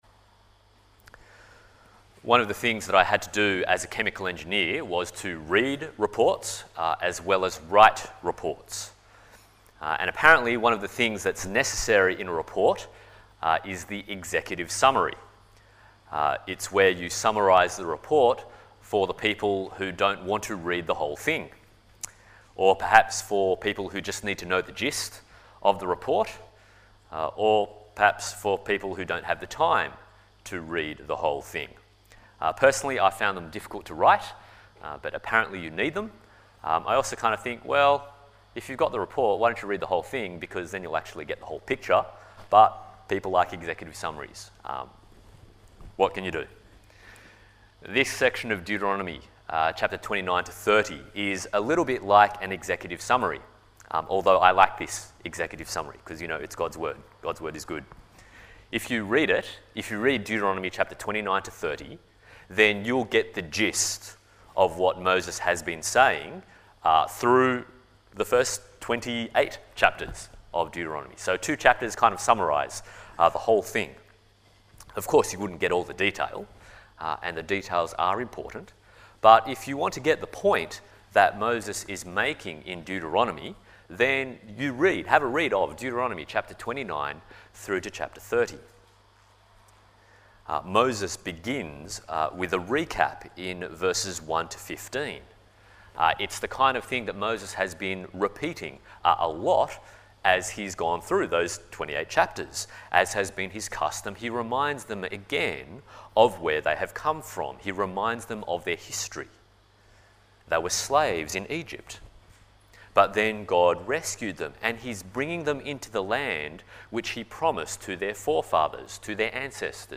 Deuteronomy Passage: Romans 10:5-13, Deuteronomy 30:11-20 Service Type: Sunday Morning « Blessings and Curses in Canaan Going Out With A Bang?